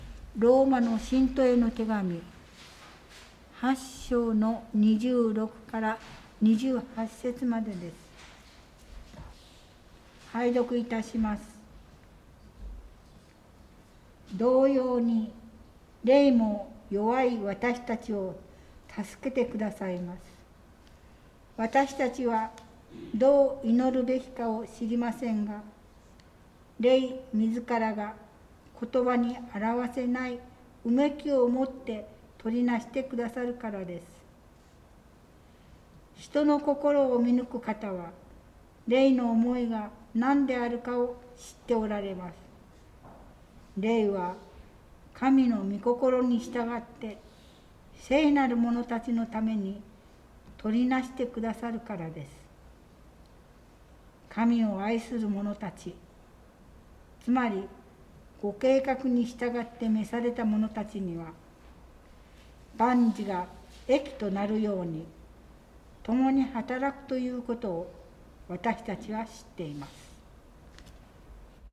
【聖書箇所朗読】